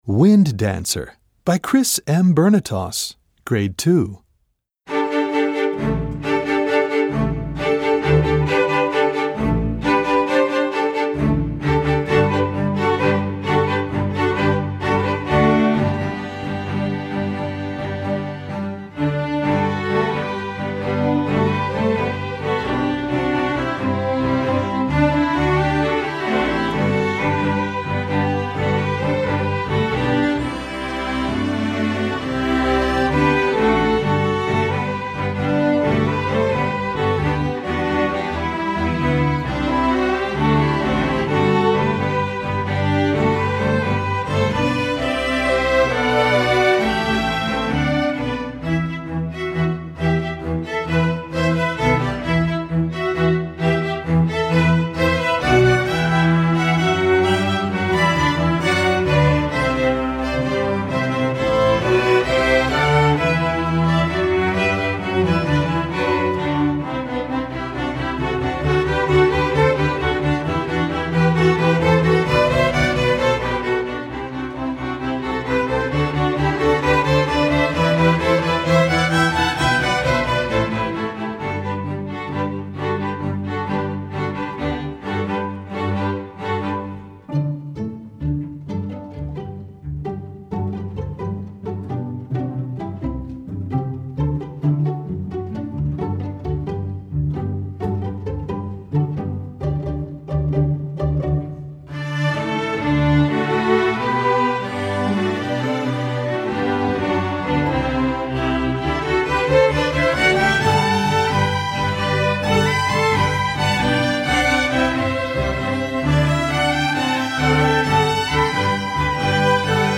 Gattung: Streichorchester
Besetzung: Streichorchester
With its optimistic and light sound